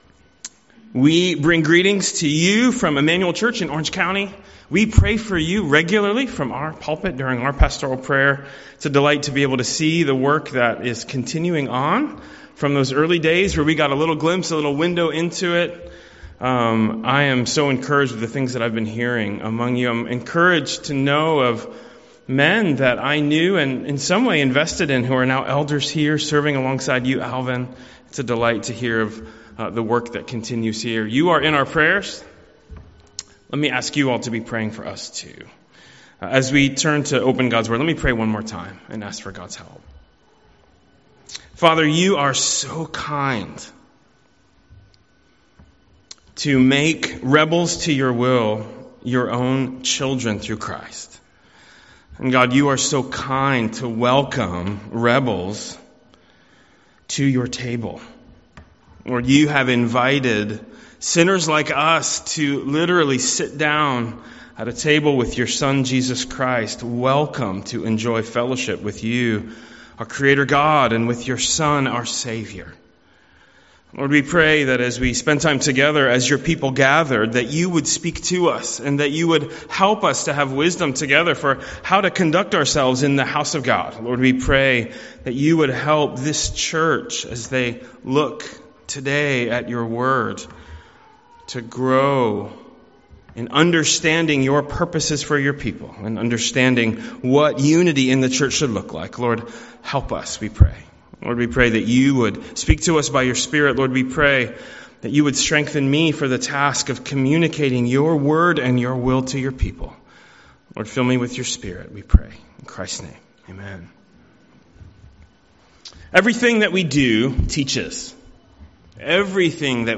A message from the series "Stand Alone Sermons."